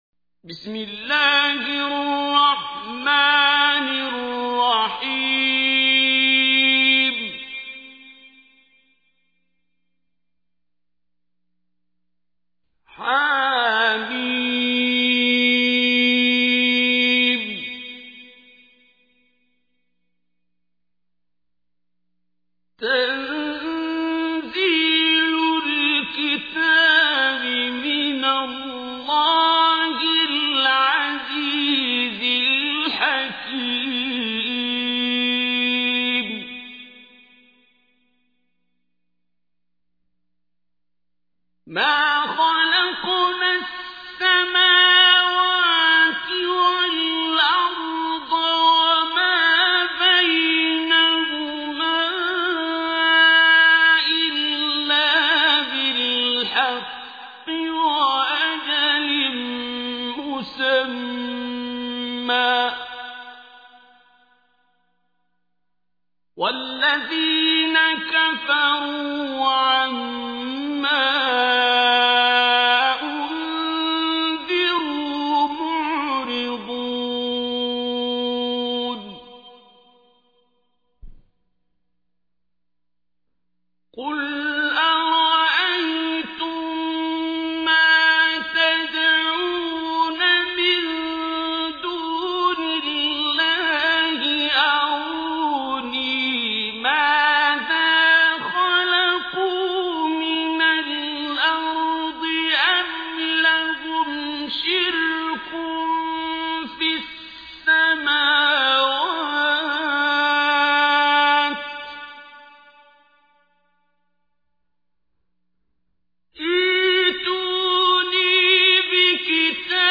Listen online and download mp3 tilawat of Surah Al Ahqaf in the voice of Qari Abdul Basit As Samad.